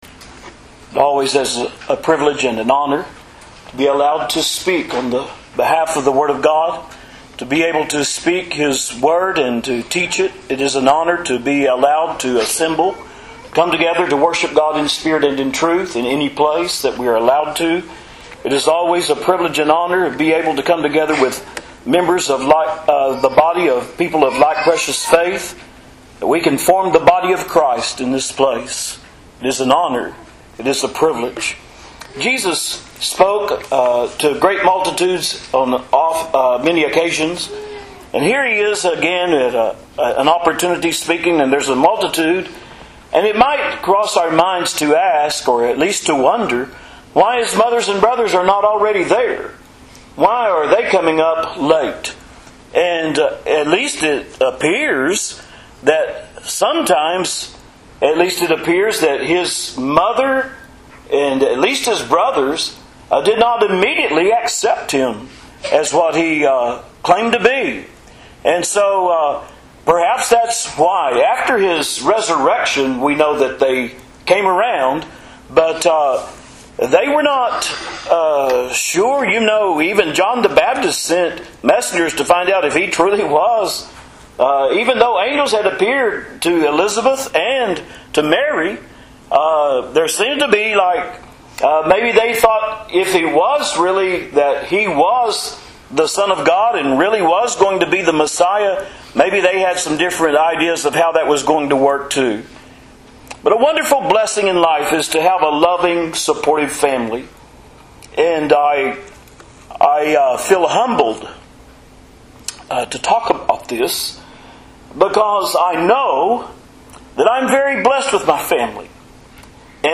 In today’s encouraging sermon